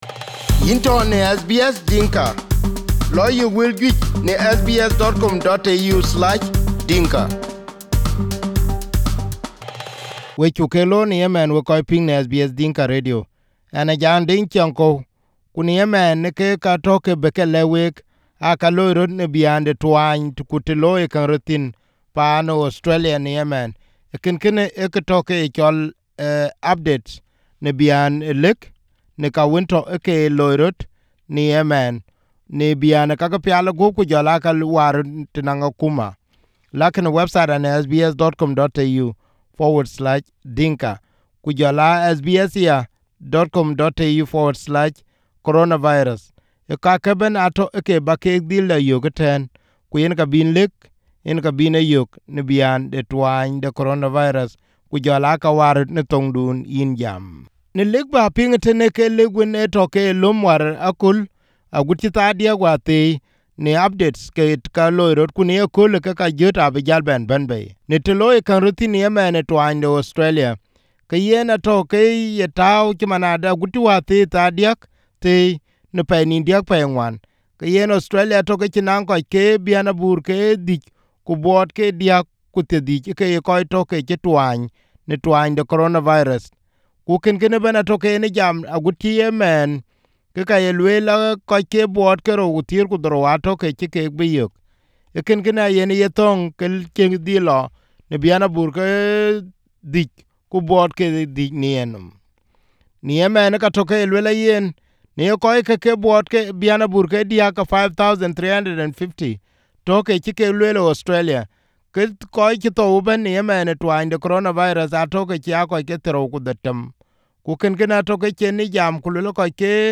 This podcast is the updates that were broadcast at 11 am on Saturday Program.